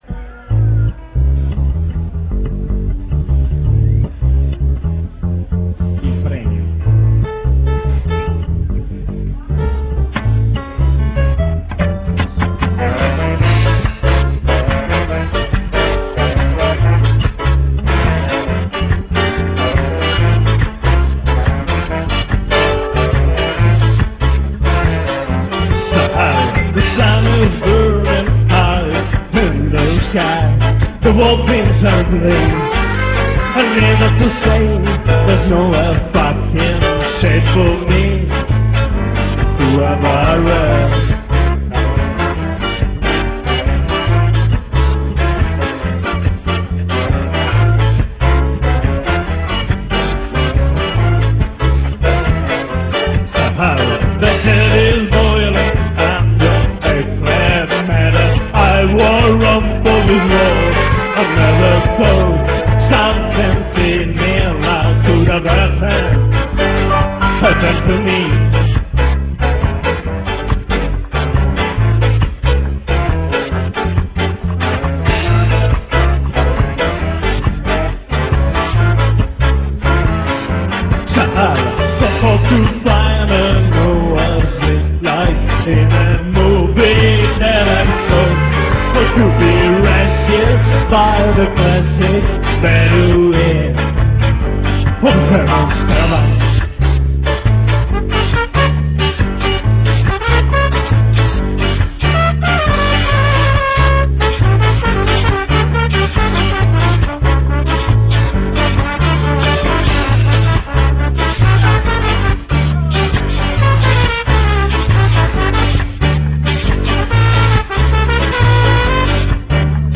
Ska-Jazz sui navigli
Assoli di piano
Il pubblico si diverte, partecipa.